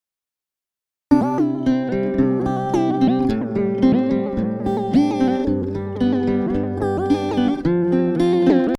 80er Gitarren-Synth-Pop-Musik
Zur Songkonstruktion und Sound möchte ich folgendes sagen: Der Bass gefällt mir soweit, spielerisch als auch...